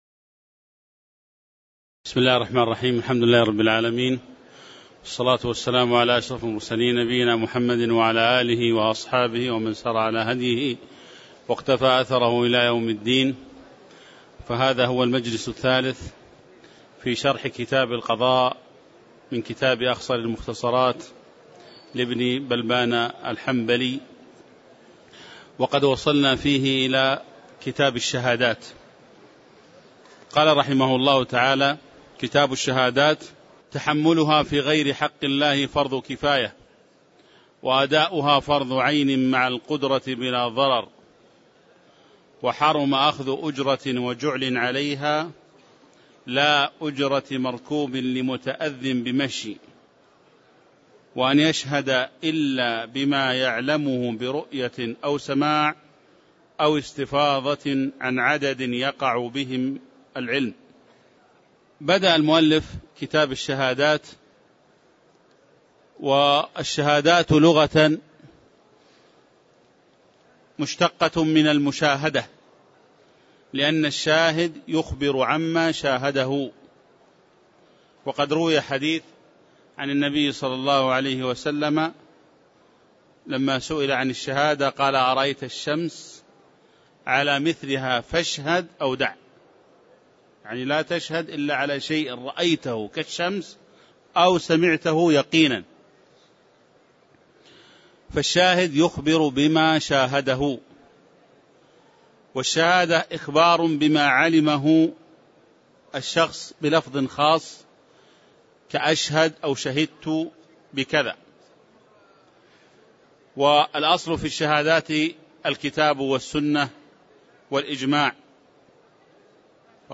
تاريخ النشر ٨ رجب ١٤٣٨ هـ المكان: المسجد النبوي الشيخ